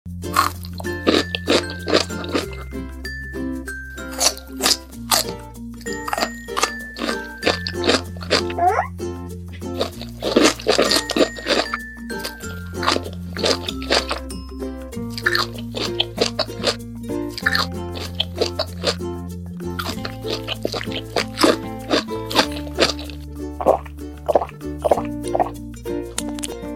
ASMR MUKBANG Animation